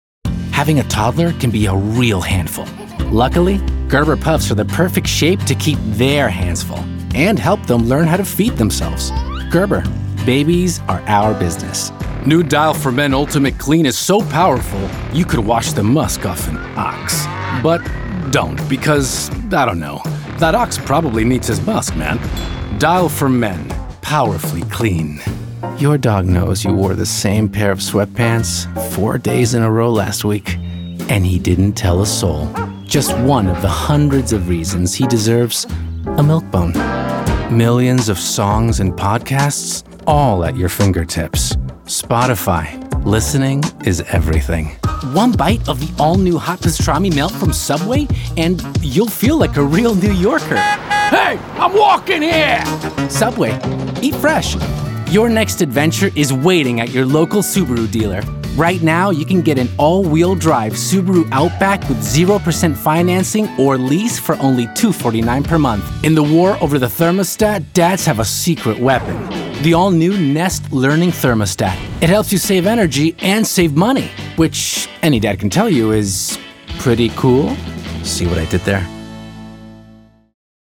Animation Demo